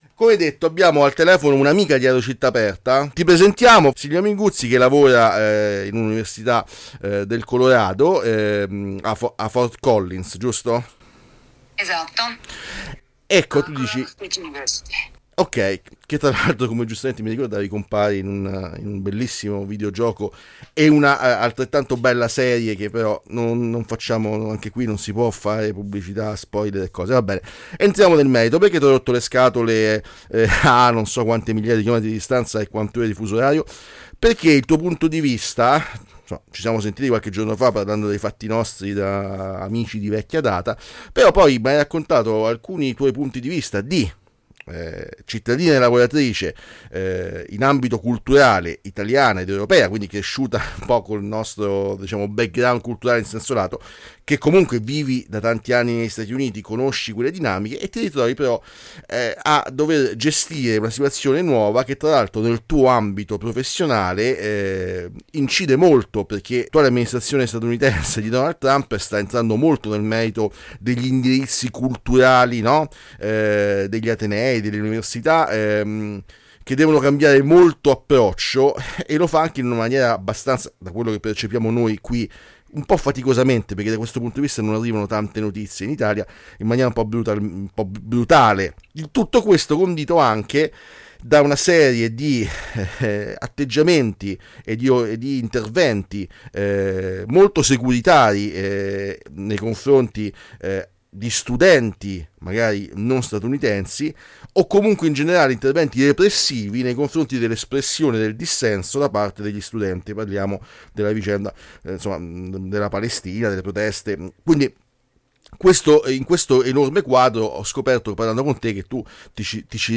Università USA: la “cura” Trump che limita pluralità e dissenso [INTERVISTA] | Radio Città Aperta